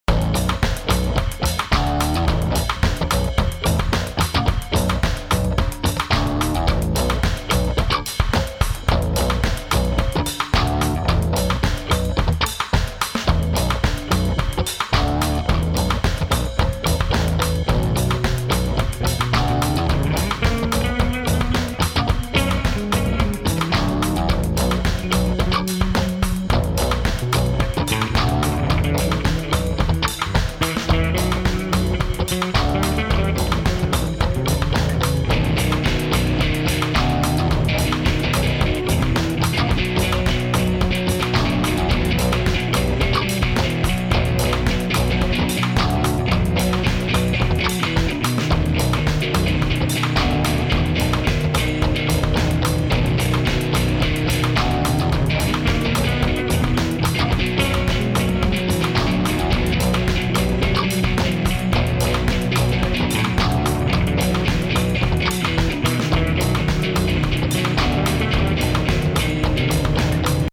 orginal 3 bass grooves